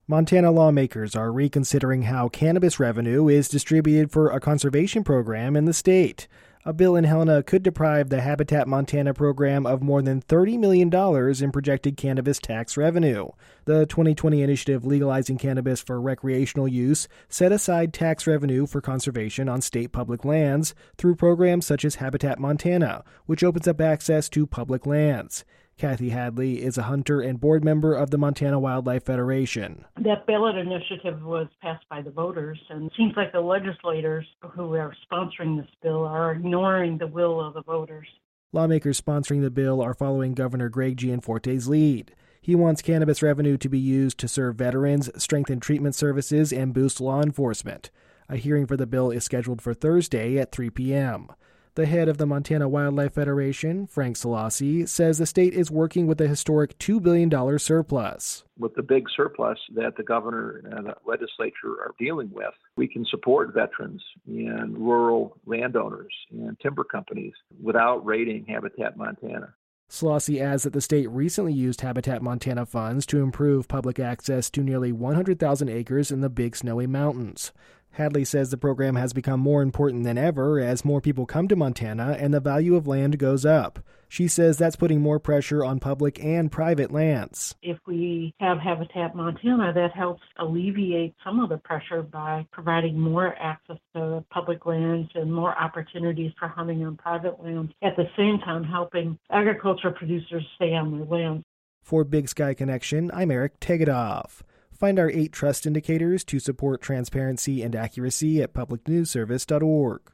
News update for Tuesday, November 11, 2025